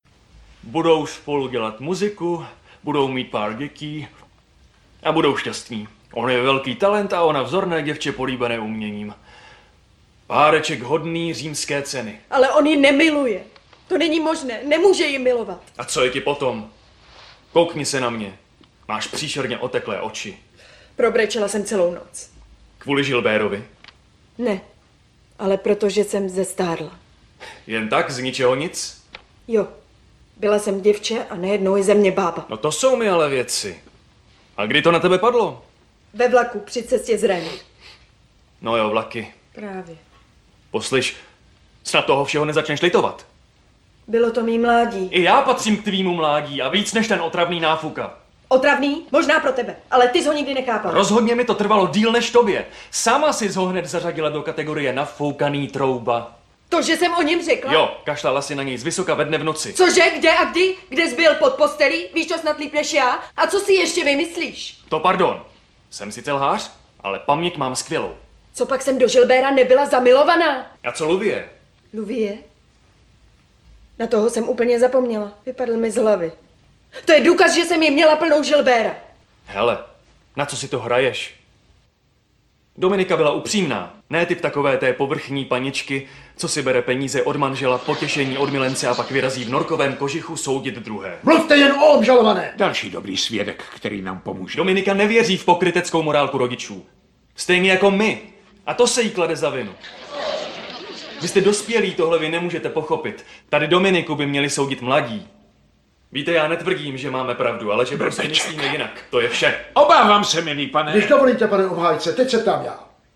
V plně vybaveném domácím nahrávacím studiu pro vás namluvím s expresní rychlostí a profesionalitou prakticky cokoliv dle vašeho přání a podmínek, na kterých se domluvíme.
Jsem profesionální herec a zpěvák s plně vybaveným domácím nahrávacím studiem a zkušenostmi, díky kterým mohu zaručit ten nejlepší výsledek.
4. DABING-Pravda (La Vérité, ČT).mp3